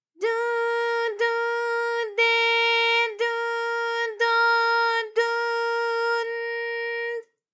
ALYS-DB-001-JPN - First Japanese UTAU vocal library of ALYS.
du_du_de_du_do_du_n_d.wav